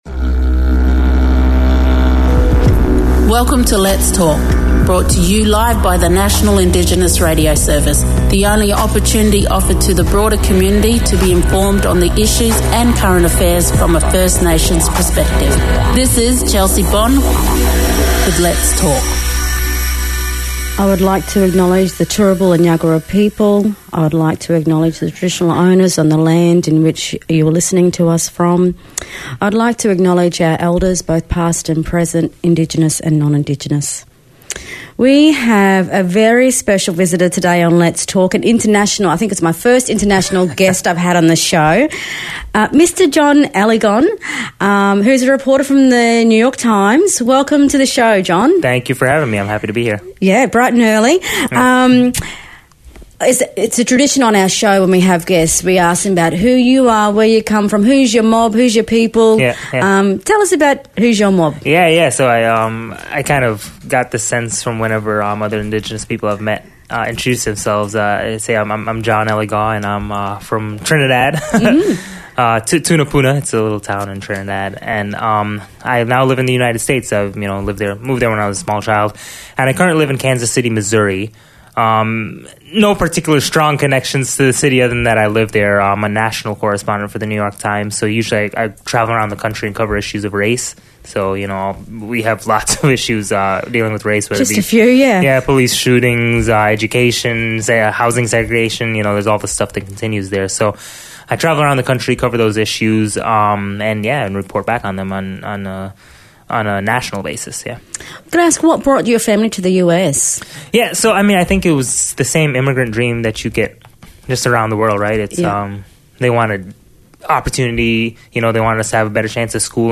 In the studio today